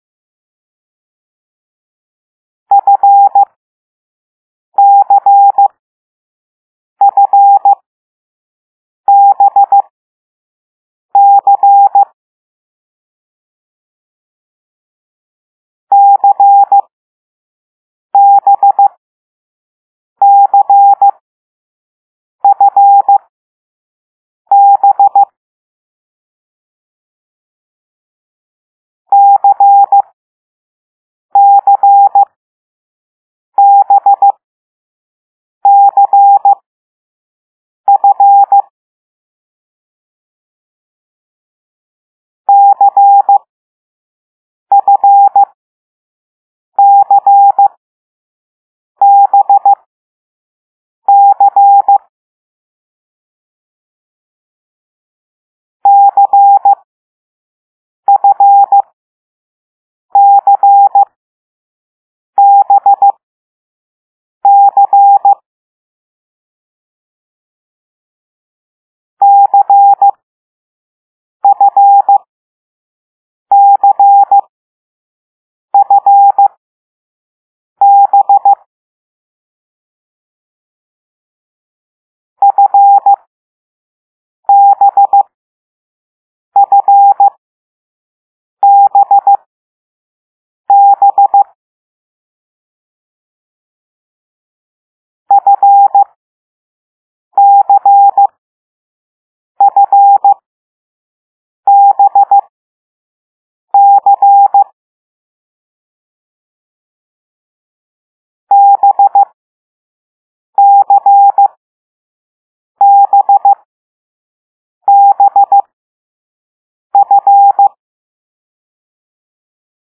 B - "dah dit dit dit"
C - "dah dit dah dit"
F - "dit dit dah dit"